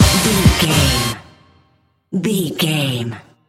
Ionian/Major
synthesiser
drum machine
Eurodance